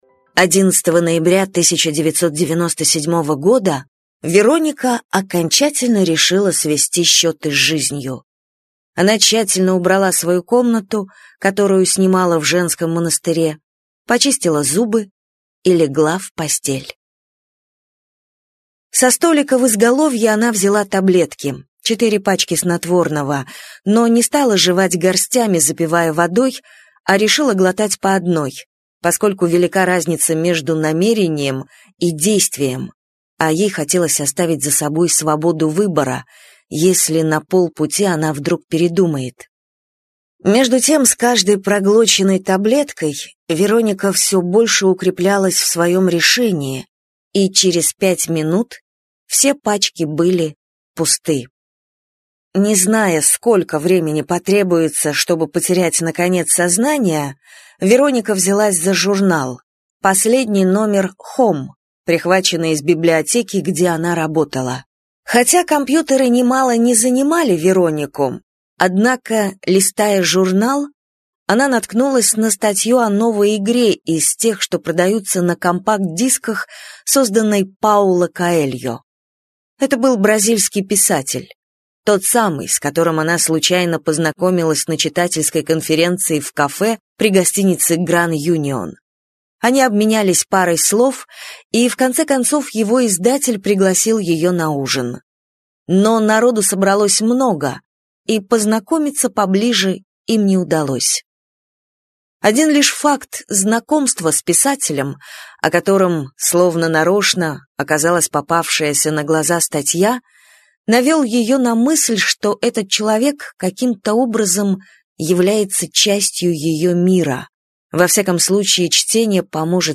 Аудиокнига Вероника решает умереть - купить, скачать и слушать онлайн | КнигоПоиск